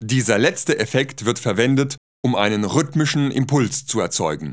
guitar
1 channel